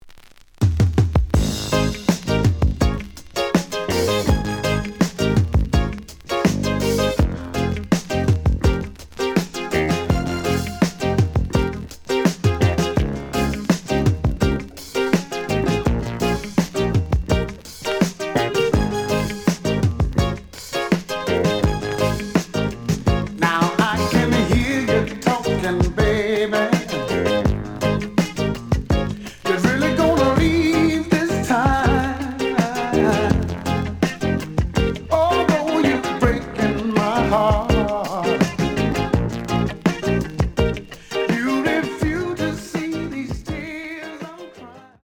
試聴は実際のレコードから録音しています。
●Genre: Soul, 80's / 90's Soul